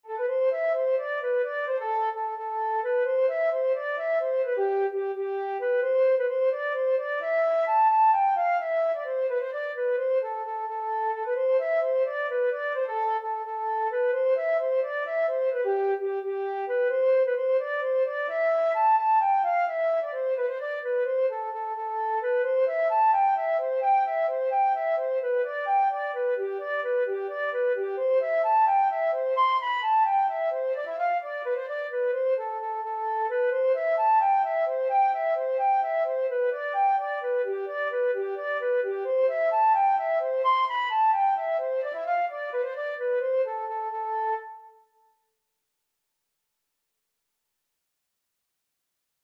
Traditional Trad. All Alive (Irish Trad) Flute version
6/8 (View more 6/8 Music)
G5-C7
A minor (Sounding Pitch) (View more A minor Music for Flute )
Flute  (View more Intermediate Flute Music)
Traditional (View more Traditional Flute Music)